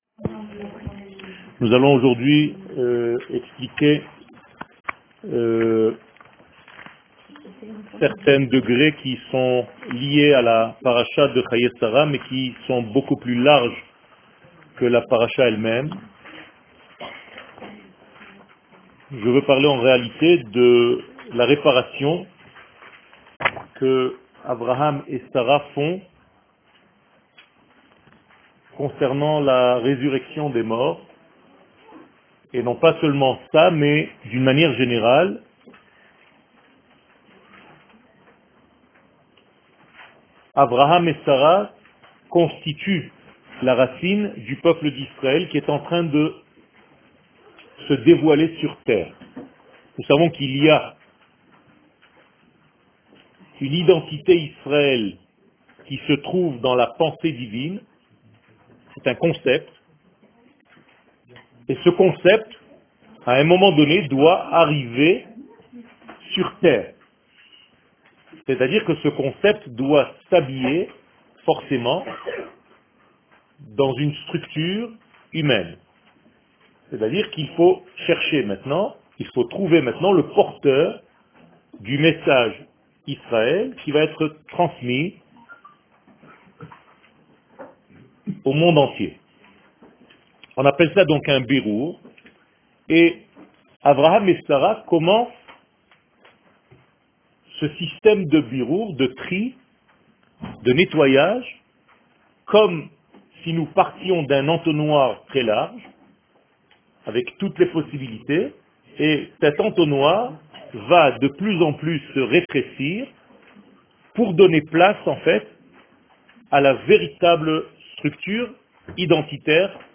שיעור